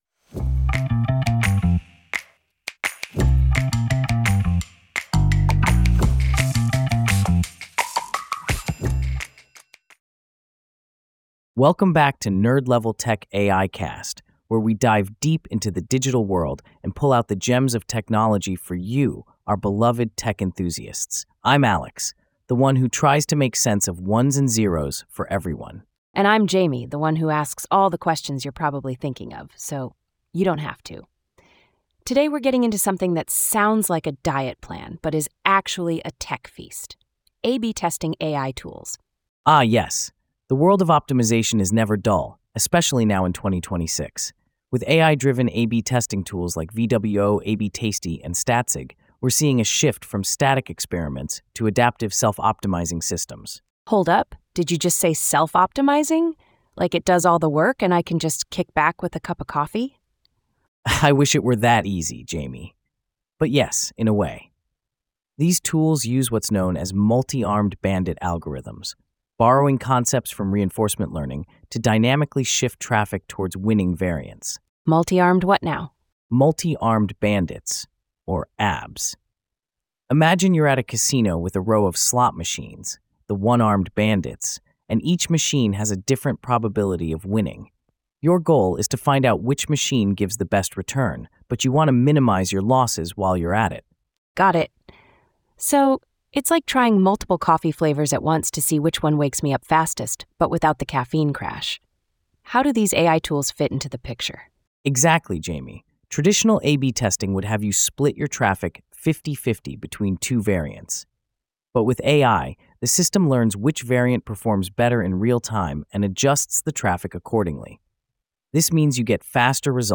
# ai-generated